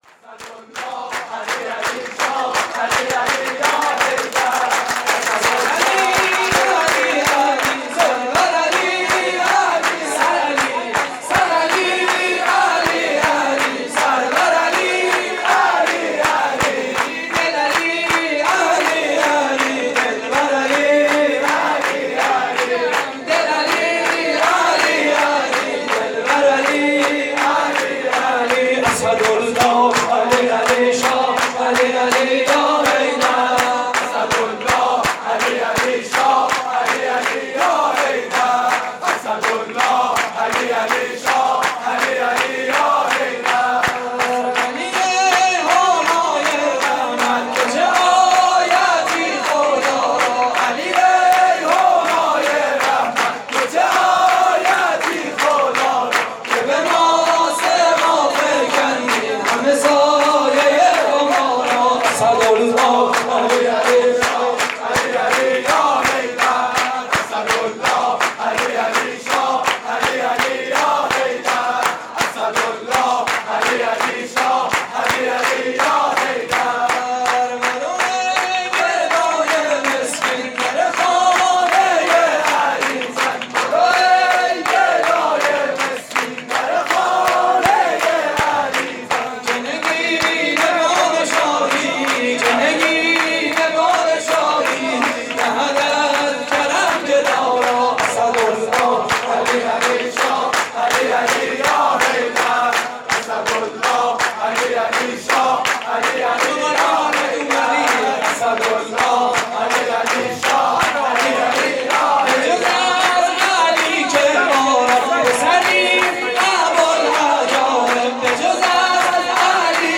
مراسم جشن ولادت حضرت امیر (ع) / هیئت کریم آل طاها (ع) - نازی‌آباد؛ 22 فروردین 96
صوت مراسم:
شور: سر علی، سرور علی؛ پخش آنلاین |